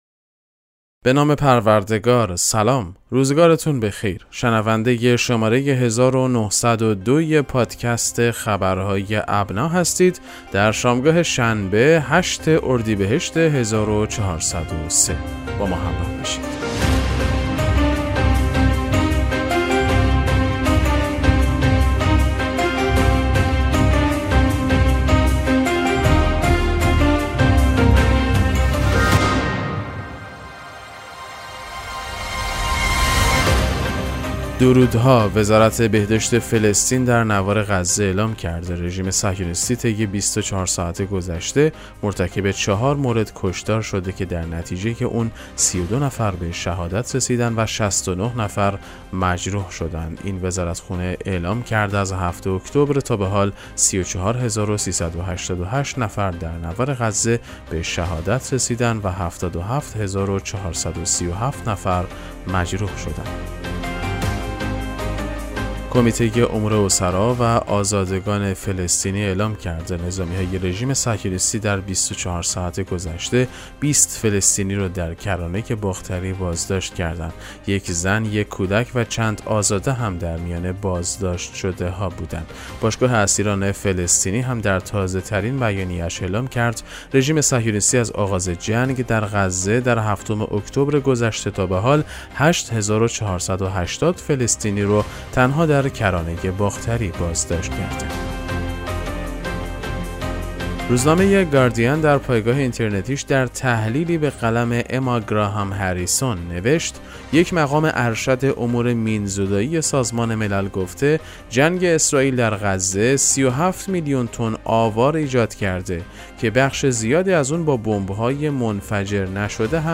پادکست مهم‌ترین اخبار ابنا فارسی ــ 8 اردیبهشت 1403